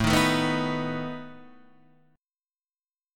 Asus2b5 chord {x 0 1 2 0 x} chord